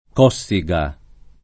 k0SSiga] cogn. — propr., forma sarda per «Corsica» (così come Cossu per «còrso»); alterato però in koSS&ga, e accettato con quest’accento dall’interessato, come cogn. del politico Francesco C. (1928-2010)